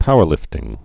(pouər-lĭftĭng)